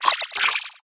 skwovet_ambient.ogg